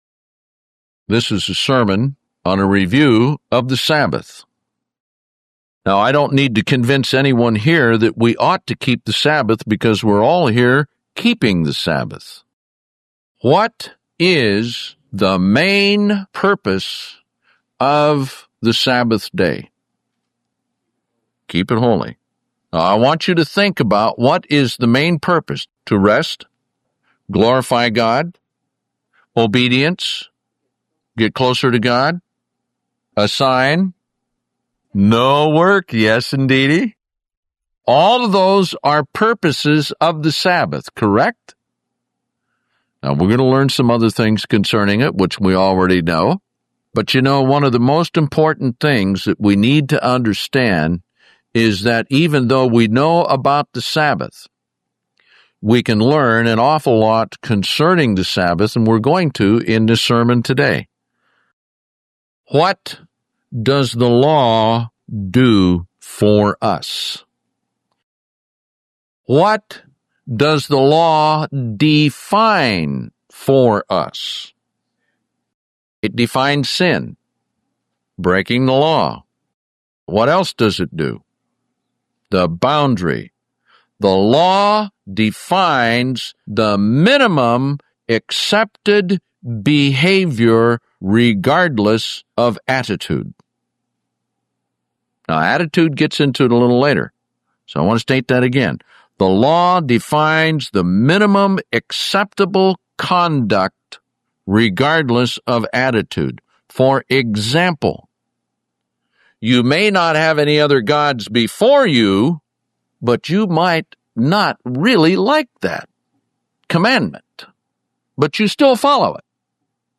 This is a sermon on a review of the Sabbath.